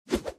sorceress_sleep_01_swing.mp3